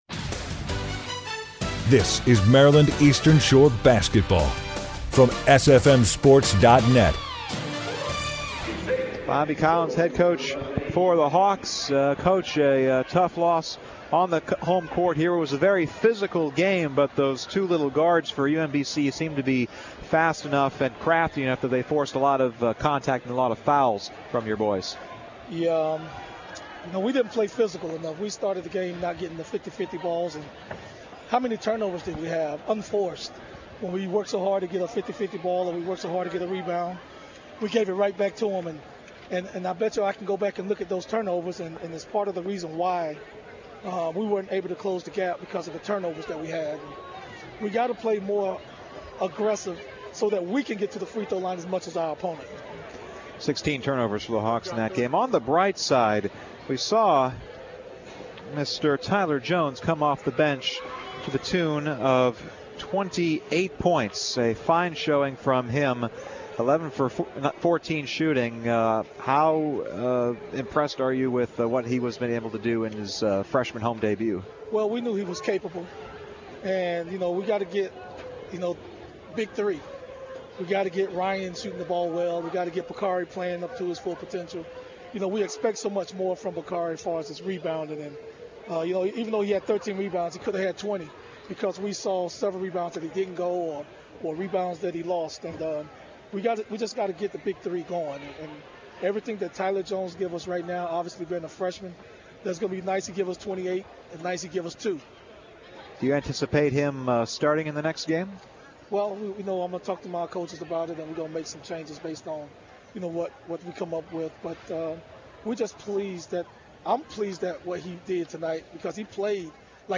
11/13/16: MD Eastern Shore Post Game Interview